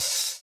DISCO 14 OH.wav